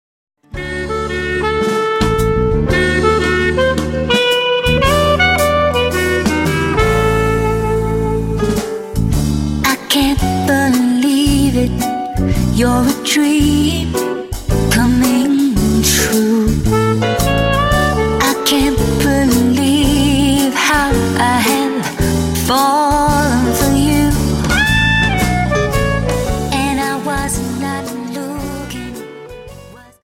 Dance: Slowfox 28s